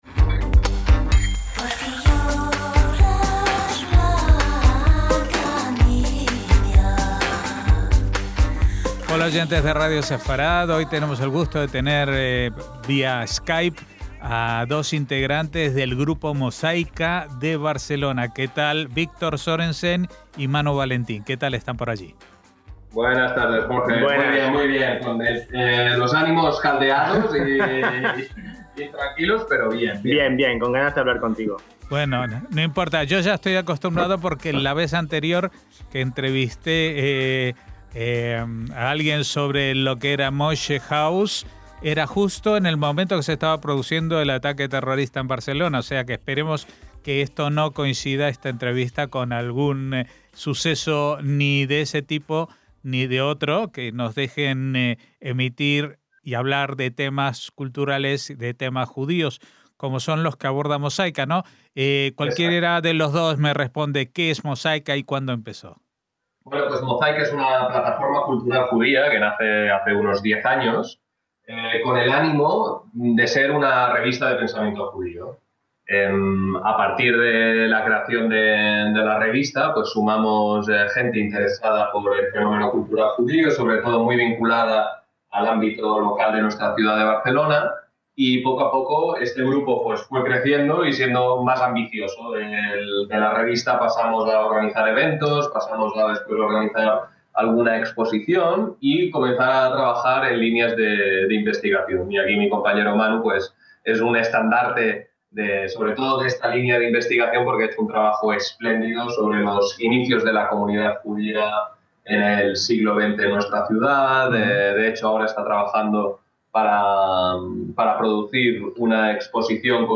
NUESTRAS COMUNIDADES - Hace ya un decenio desde que un grupo de jóvenes de las comunidades judías de Barcelona pusieron en marcha una revista, Mozaika, en torno a la cual se fue gestando con el paso del tiempo un terreno fértil para iniciativas culturales y de investigación en torno al tema, que han cuajado en proyectos como la reciente celebración conjunta de los años nuevos judío y musulmán con gente de aquellas comunidades, o la puesta en marcha de una feria del libro judío, Sefer, que este año pretenden financiar mediante una suscripción popular (crowdfunding). Hablamos con dos de sus más activos miembros